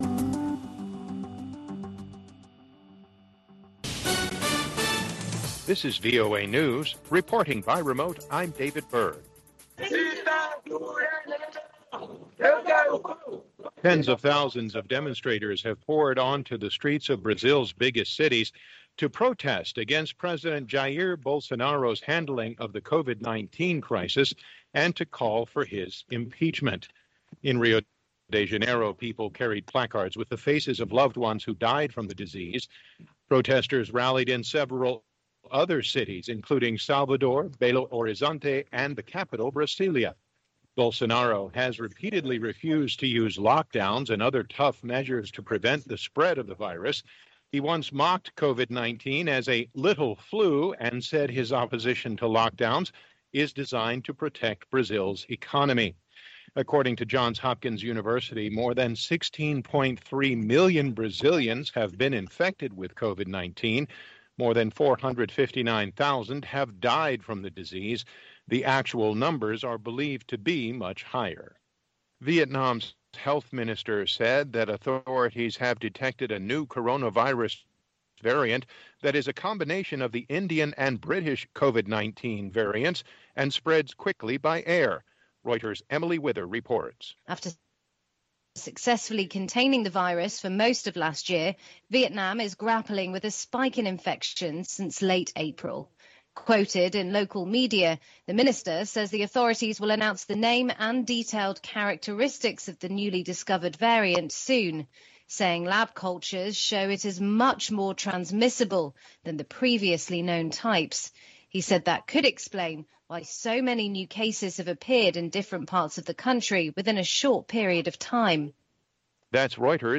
Two Minute Newscast